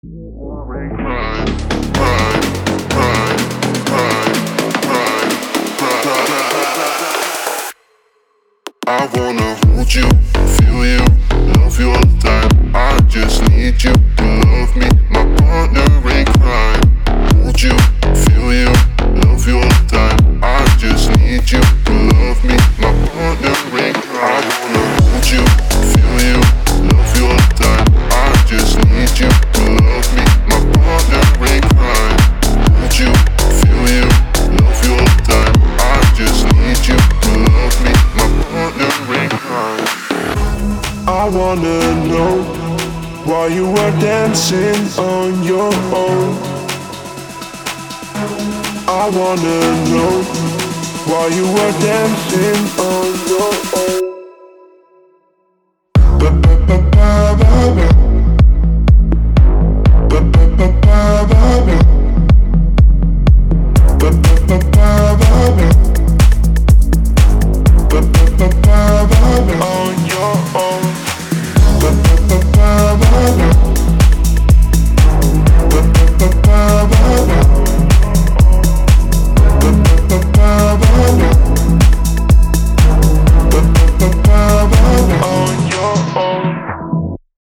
我们组建了一个选择的高品质鼓采样，预置位，项目，人声和循环！